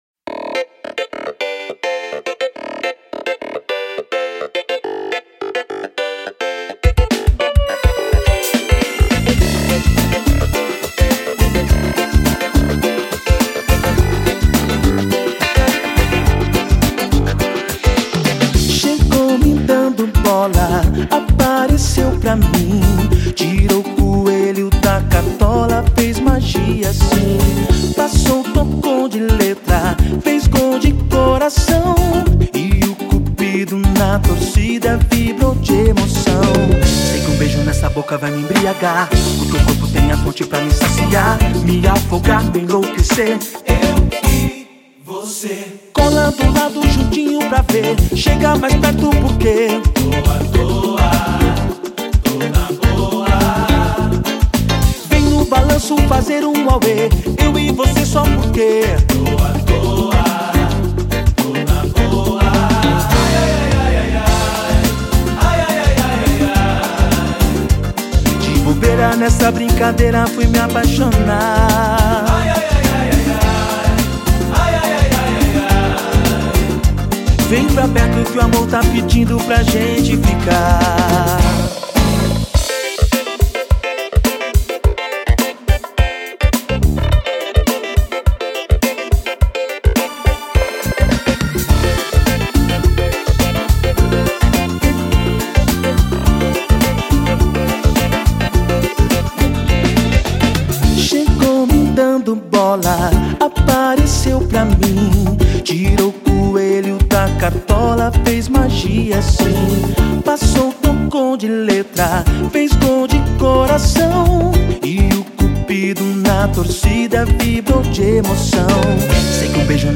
EstiloSamba Rock